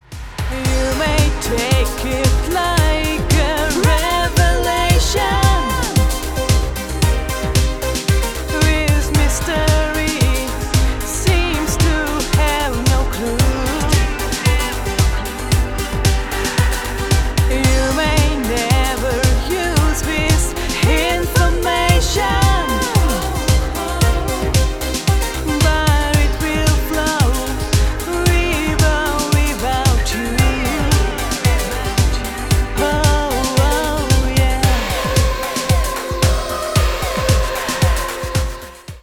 диско
поп